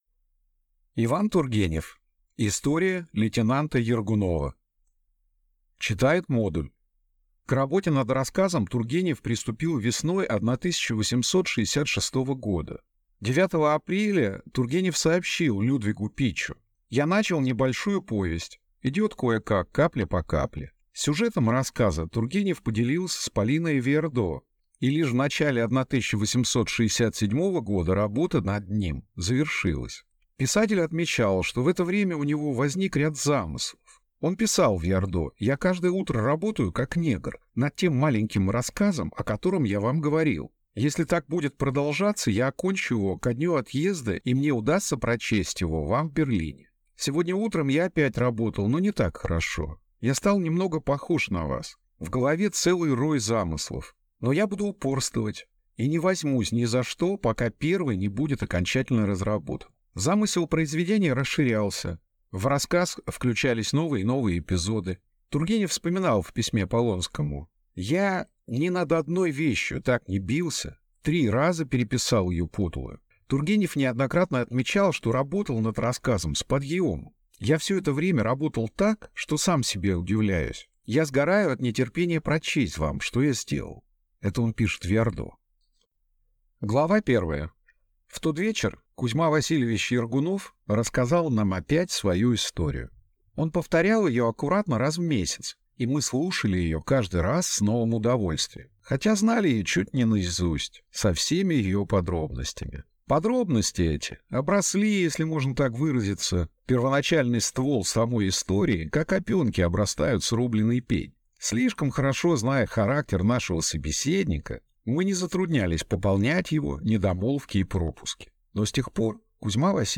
Аудиокнига История лейтенанта Ергунова | Библиотека аудиокниг